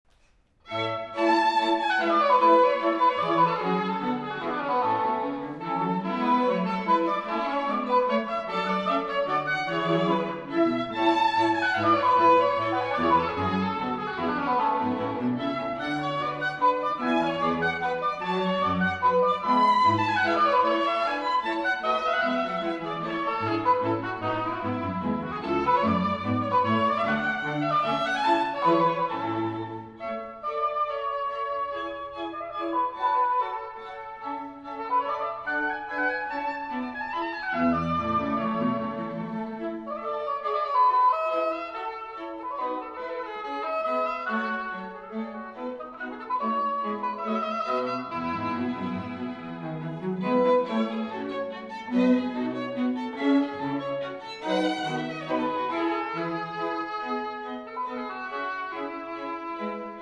live performance on October 1, 1989
Oboe
Strings
Violin